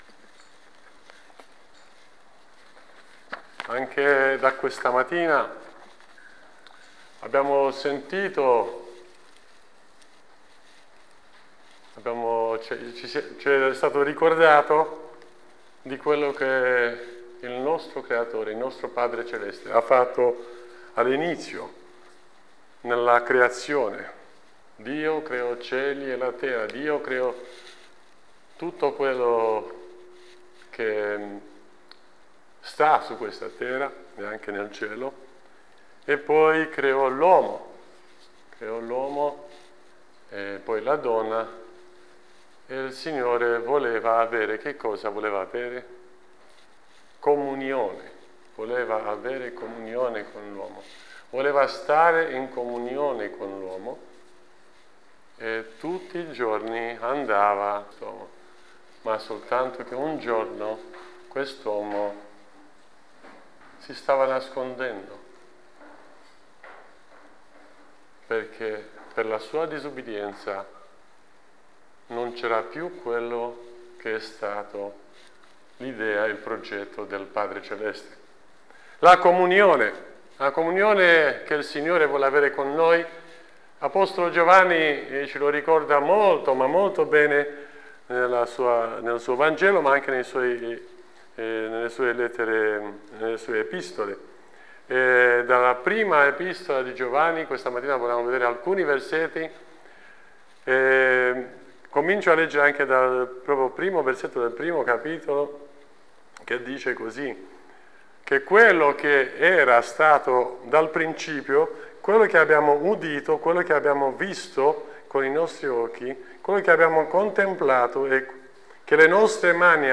Insegnamenti sul passo di 1 Giovanni 2:7-17.